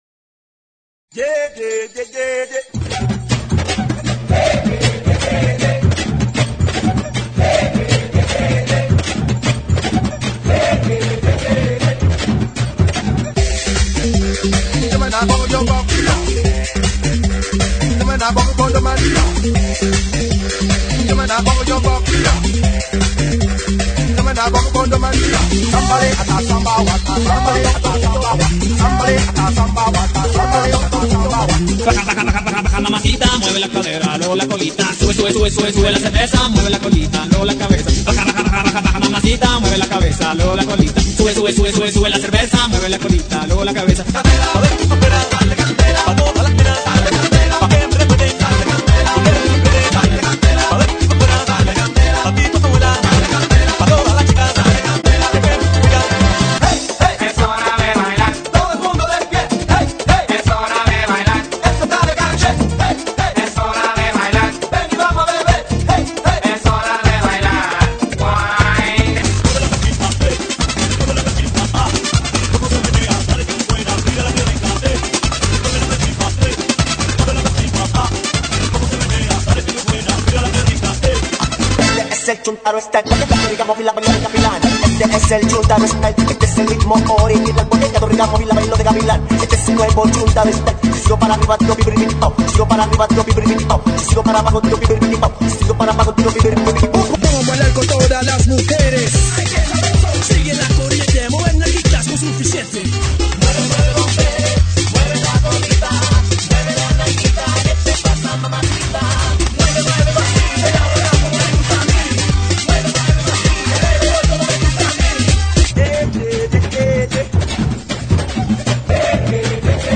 GENERO: LATINO